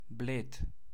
Bled (pronounced [ˈbléːt]